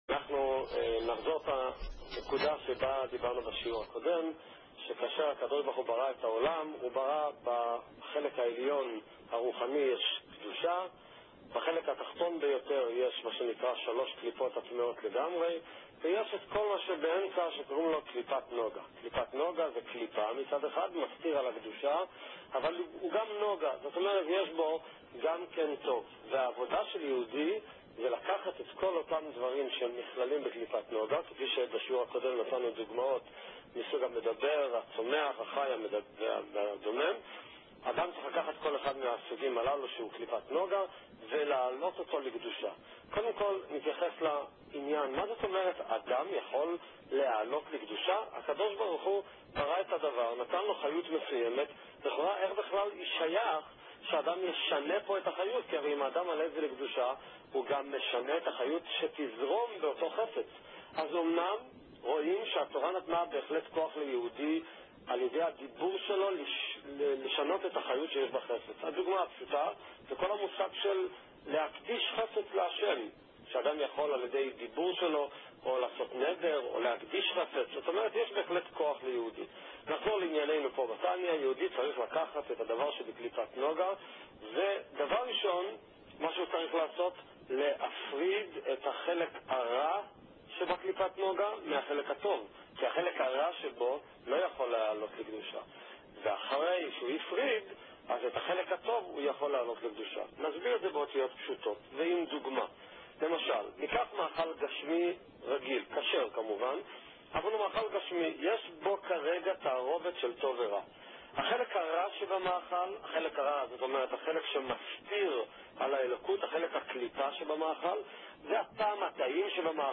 שיעורי תניא | חומש עם רש״י | שיעורי תורה